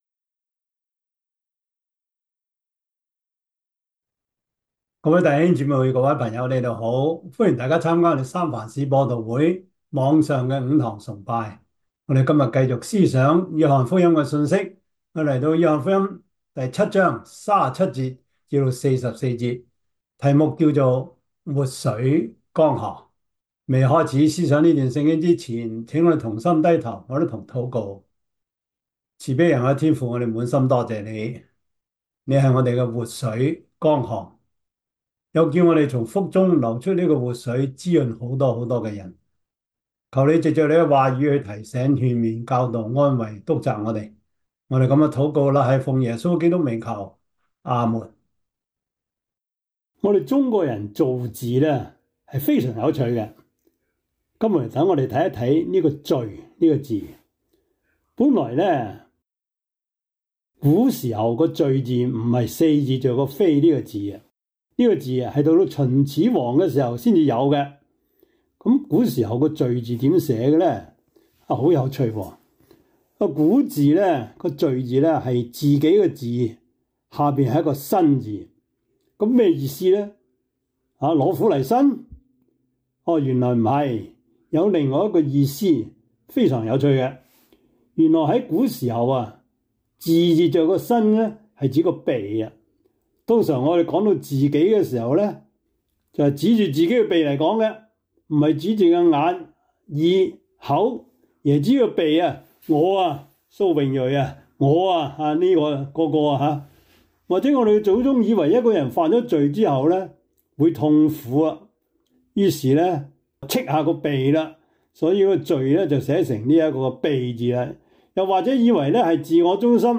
約翰福音 7:37-44 Service Type: 主日崇拜 約翰福音 7:37-44 Chinese Union Version